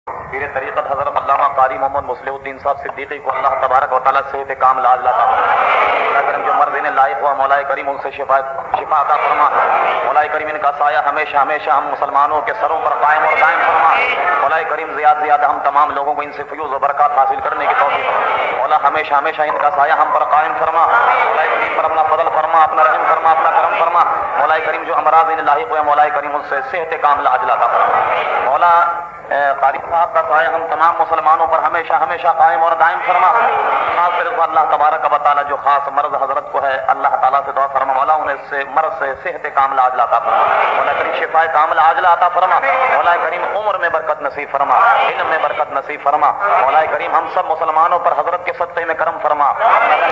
Category : Speech | Language : Urdu